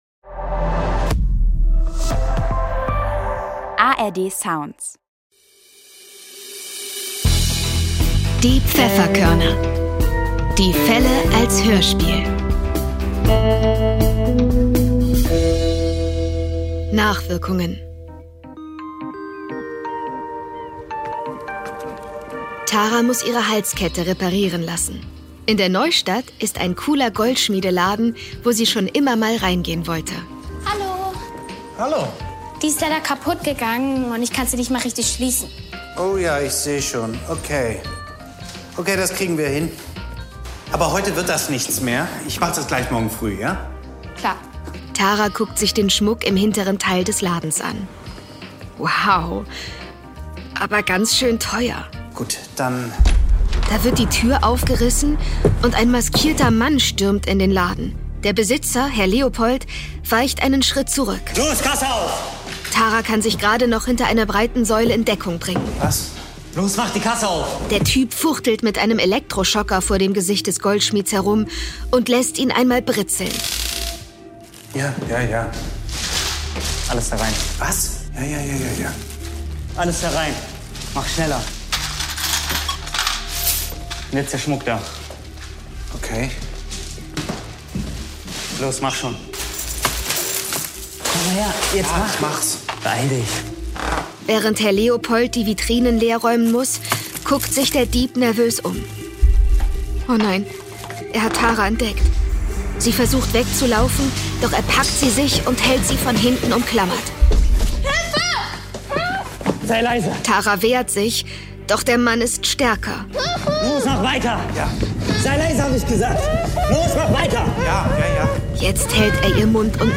Die Pfefferkörner - Die Fälle als Hörspiel Podcast